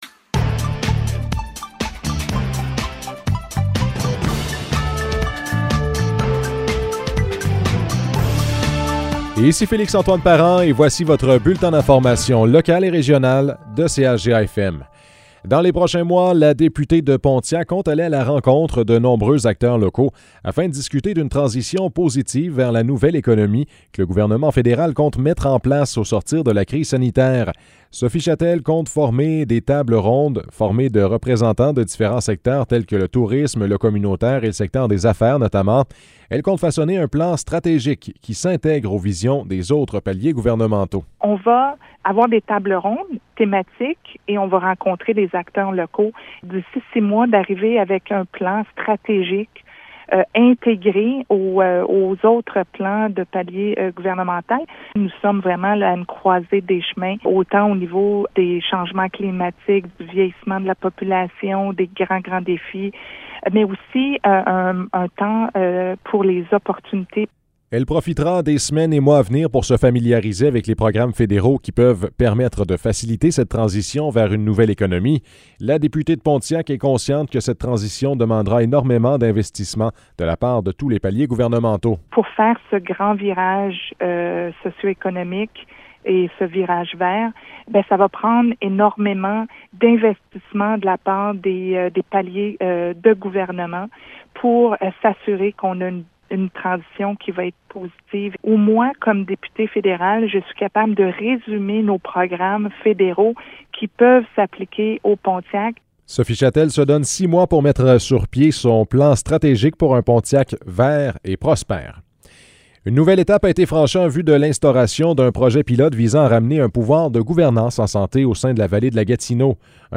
Nouvelles locales - 19 octobre 2021 - 12 h